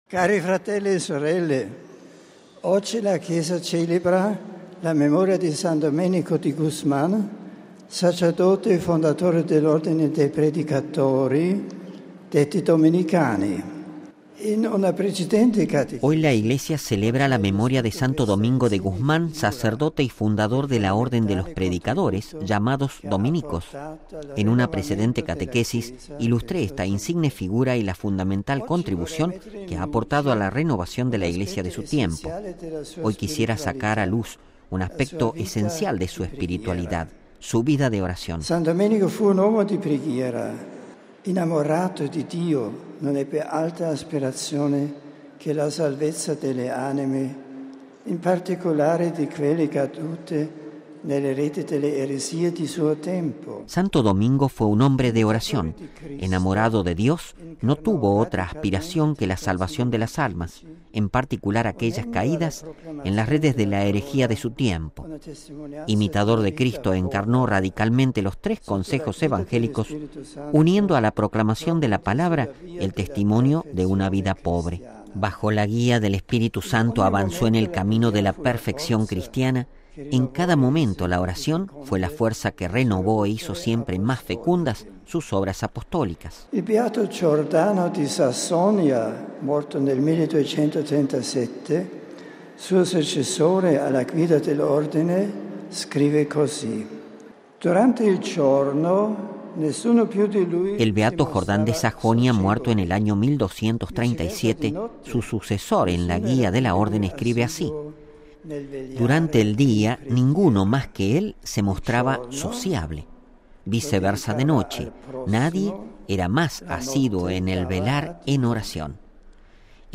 En efecto en su breve catequesis pronunciada en Castel Gandolfo y solo en idioma italiano, el Papa aludió a que Santo Domingo de Guzmán con sus actitudes nos enseña la importancia de las actitudes exteriores de nuestra propia oración.